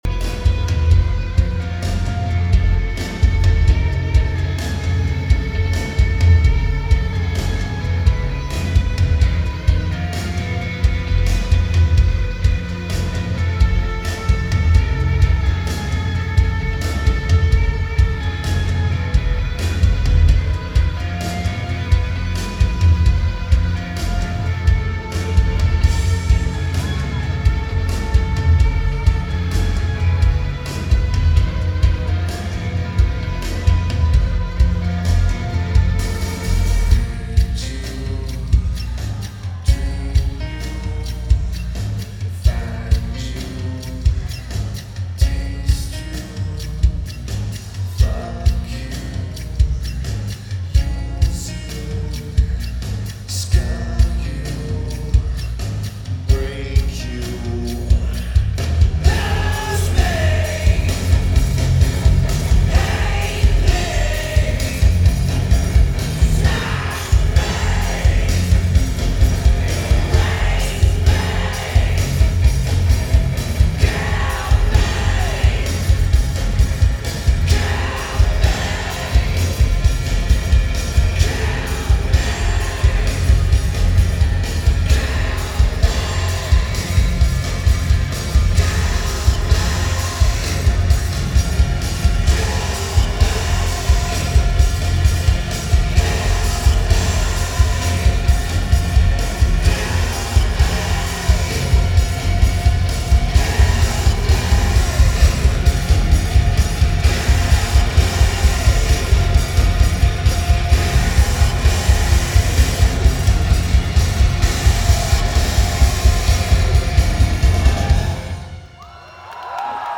Brixton Academy
Lineage: Audio - AUD (Core Sound HEB + BB + Sony TCD-D100)